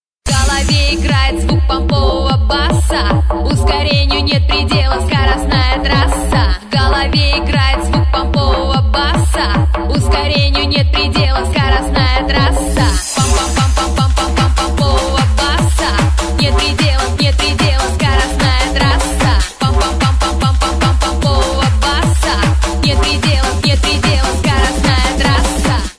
• Качество: 128, Stereo
громкие
мощные басы
энергичные
быстрые
Хардбасс